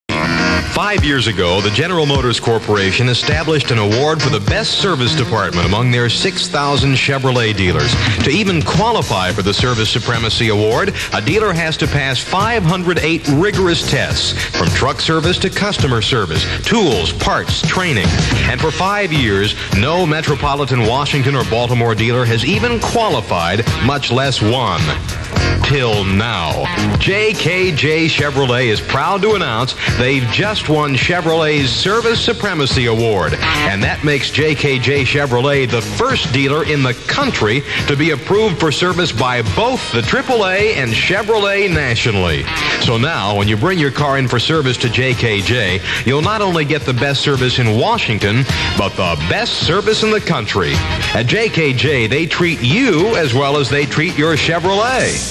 In House Spots